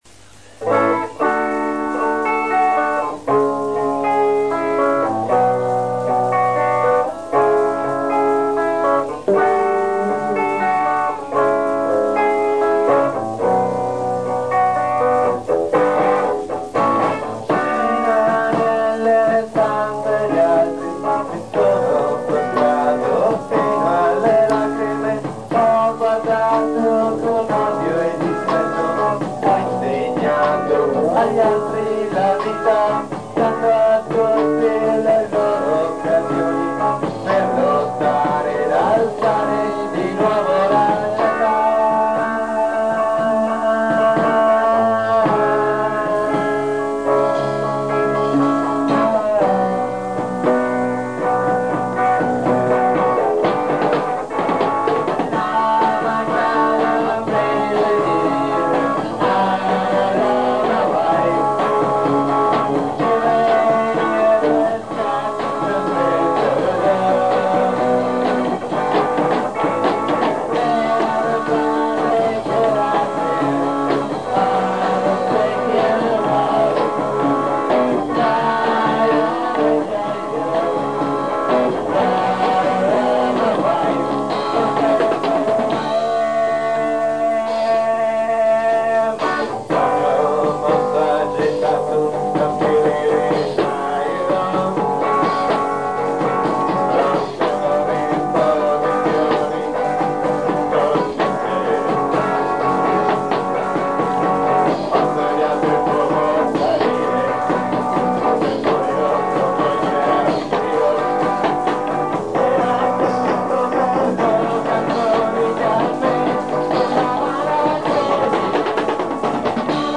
voce, chitarra
basso
batteria, voce
Prove registrate nel Dicembre 1985